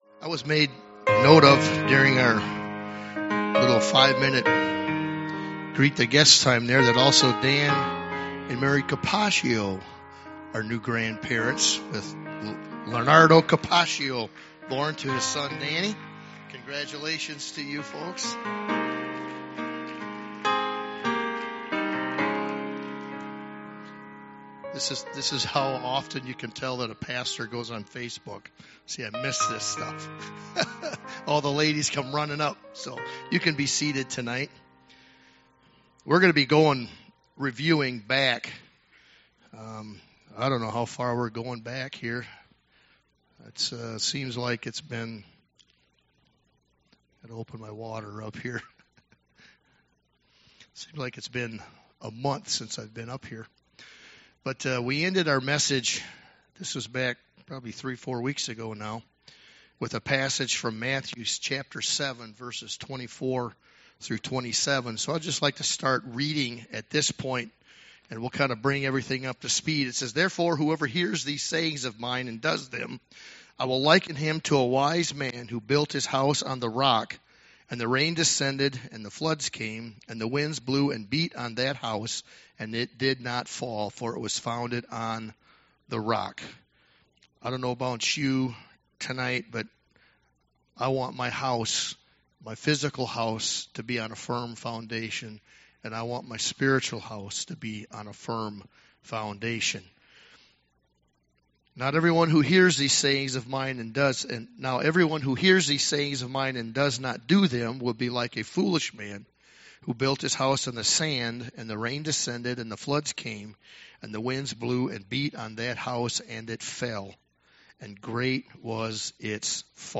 A message from the series "Calvary Gospel Church."